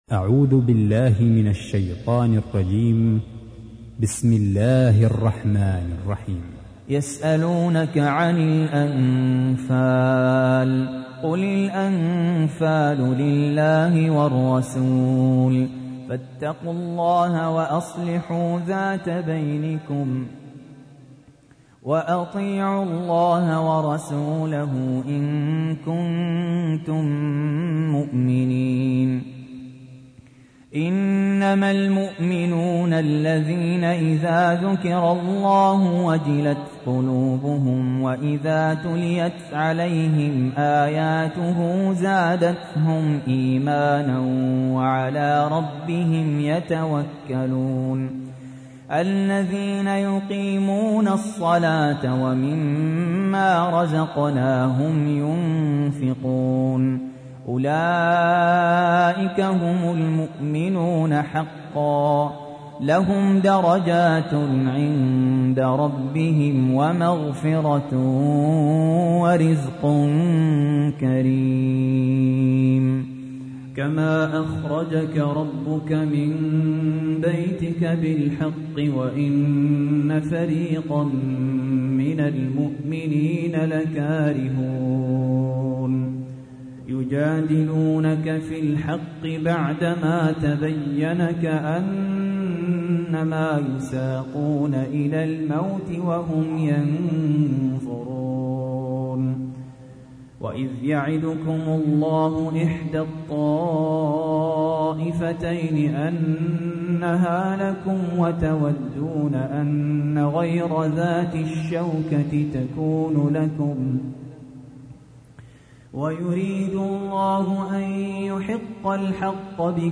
تحميل : 8. سورة الأنفال / القارئ سهل ياسين / القرآن الكريم / موقع يا حسين